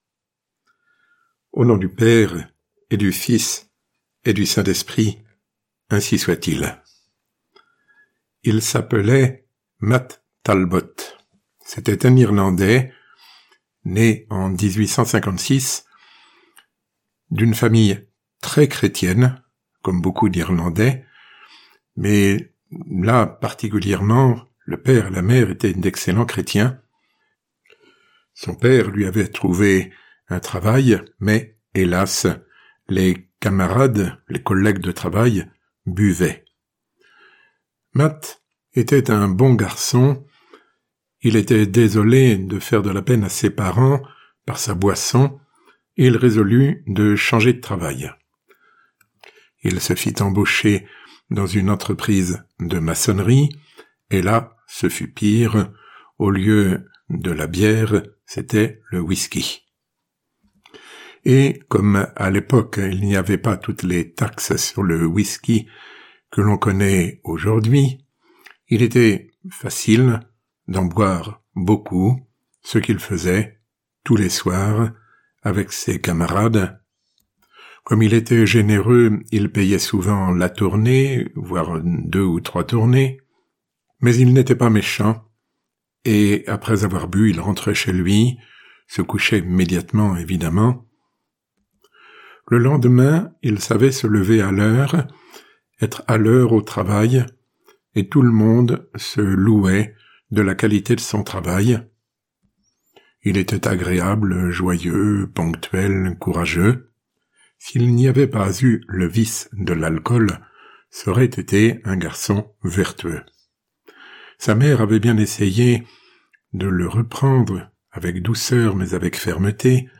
Sermon ~ Matt Talbot Sortir de la dépendance du vice et vivre pour Dieu